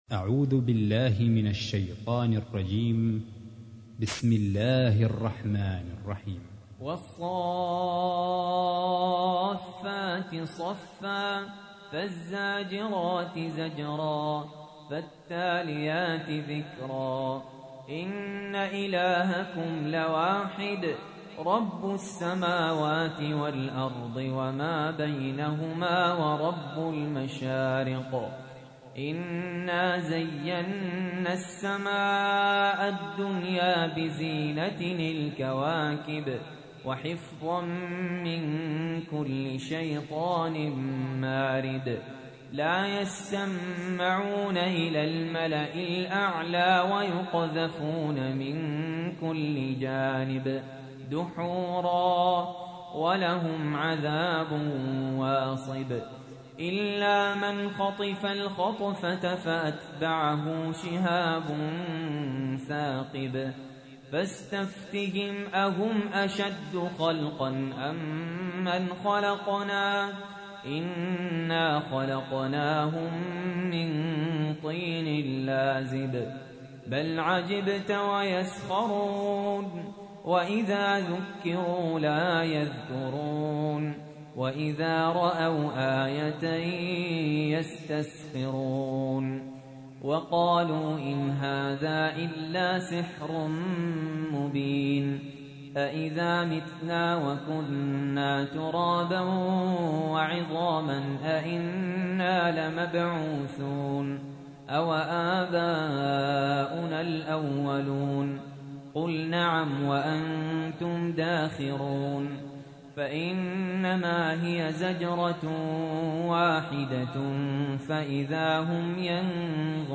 تحميل : 37. سورة الصافات / القارئ سهل ياسين / القرآن الكريم / موقع يا حسين